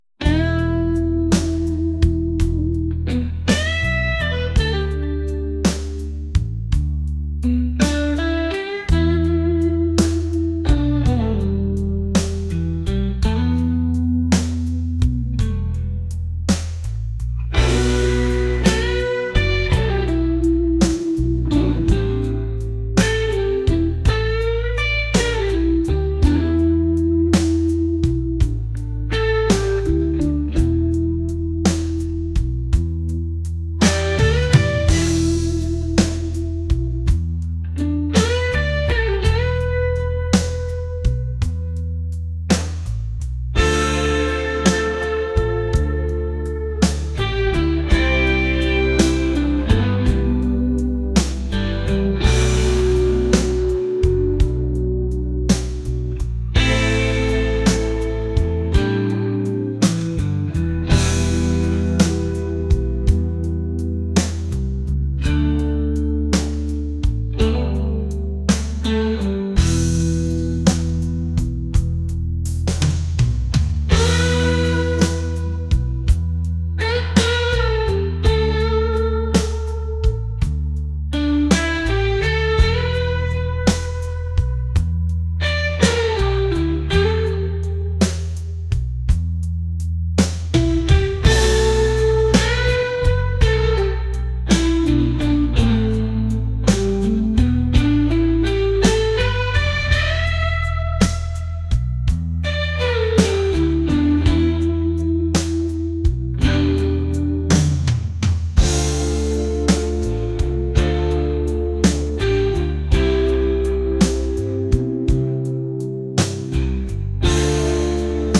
rock | acoustic | laid-back